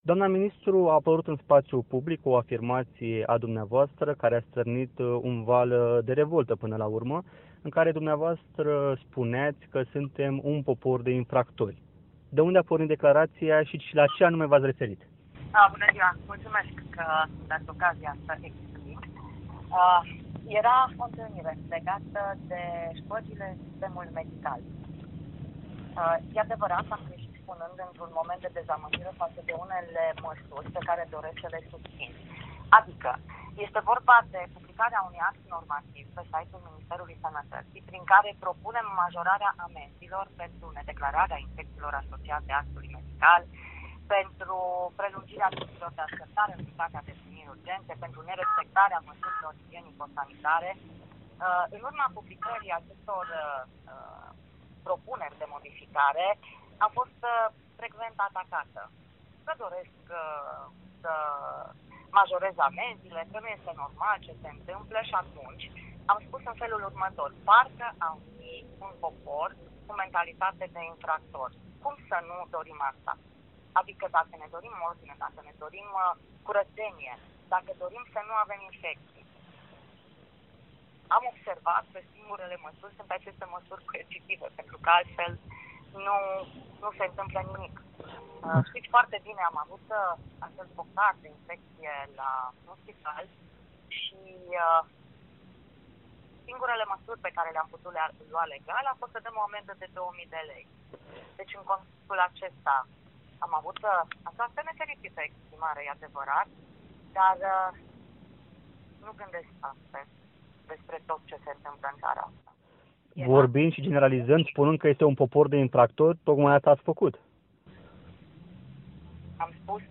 Interviu cu Sorina Pintea despre declarația sa controversată